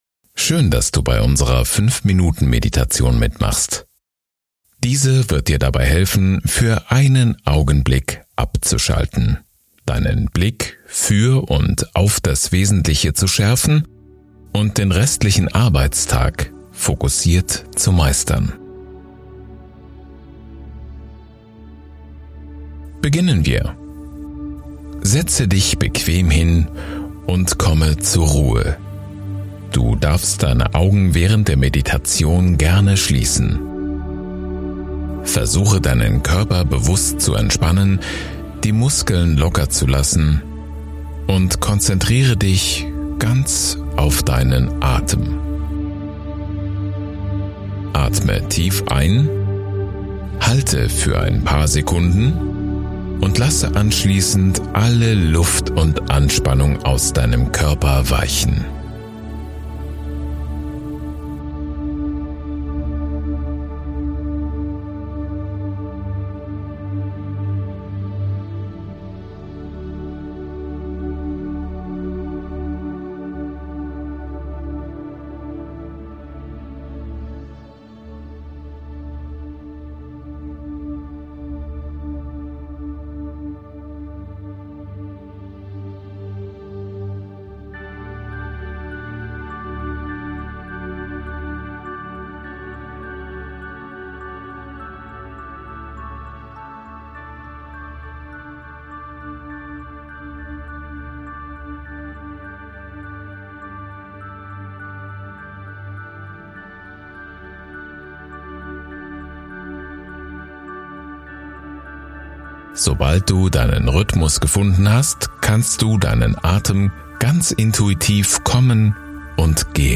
Unsere hauseigenen Sprecher führen professionell durch die selbst konzipierten Meditationen.
5-Minuten-Meditation